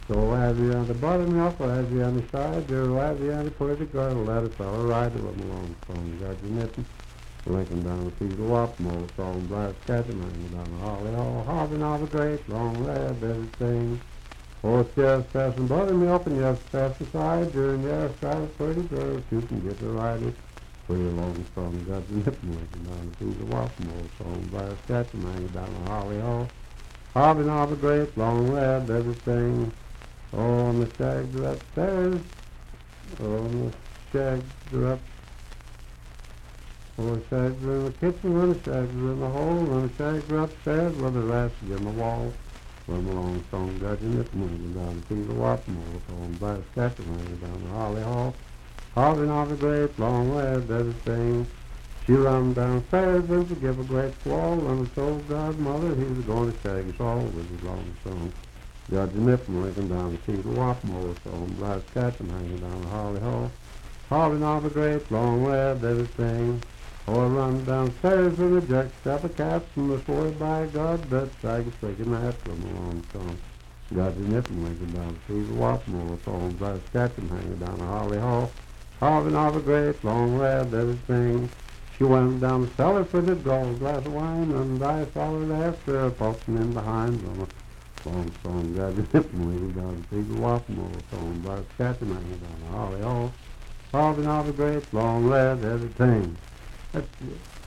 Unaccompanied vocal music
Bawdy Songs
Voice (sung)
Fairview (Marion County, W. Va.), Marion County (W. Va.)